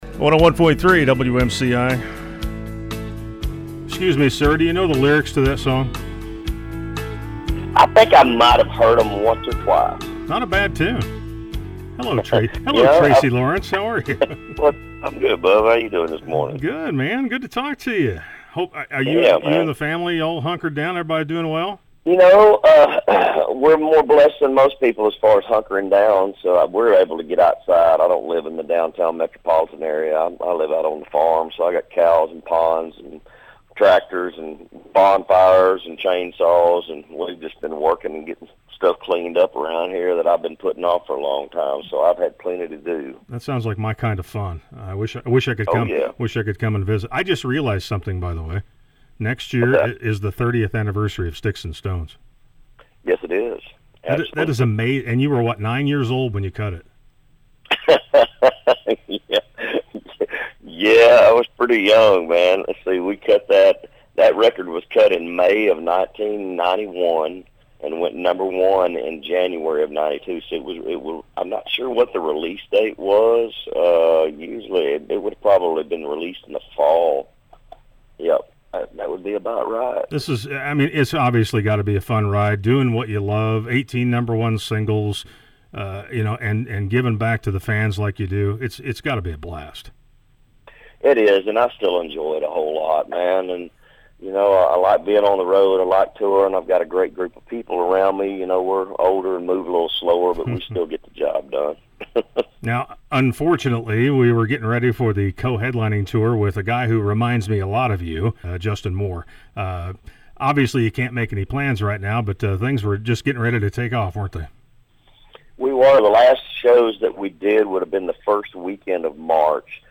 Tracy Lawrence visited with the Yawn Patrol on Thursday, April 23.